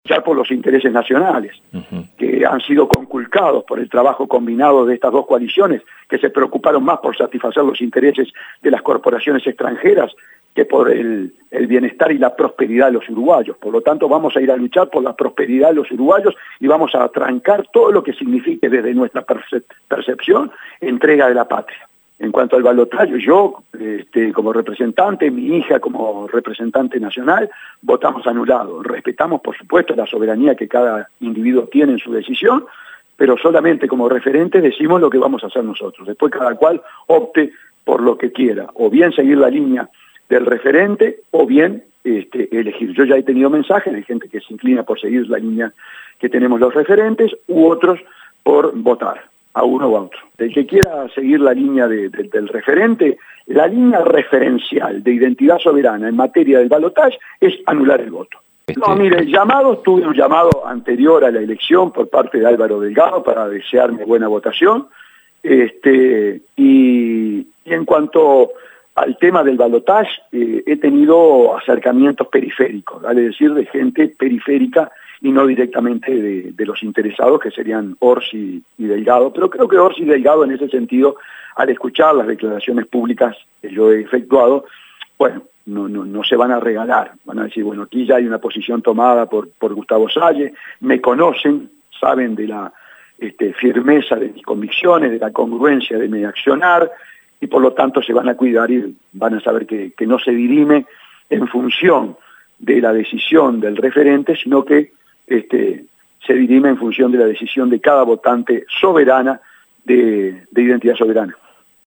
Salle dijo a Radio Monte Carlo de Montevideo, que ambos bloques que dirimirán la presidencia del país, se han preocupado más por satisfacer los intereses del corporaciones extranjeras, que por la prosperidad de los uruguayos.